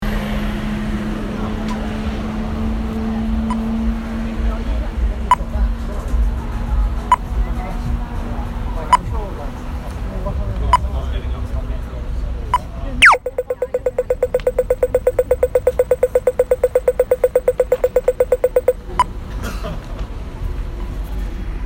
Auckland street crossing
Recorded entirely on an iPhone 6s, the album is designed to be listened to in a single sitting, it’s 23 tracks blending continuously to unfurl the story of a unique journey around the world.